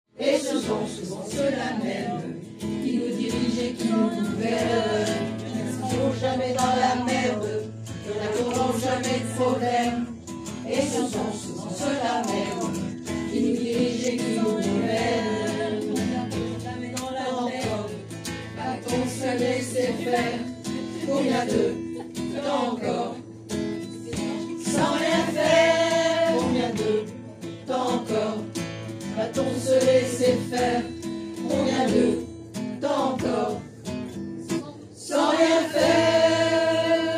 Visite gourmande de aujourd'hui
et on à aussi la chorale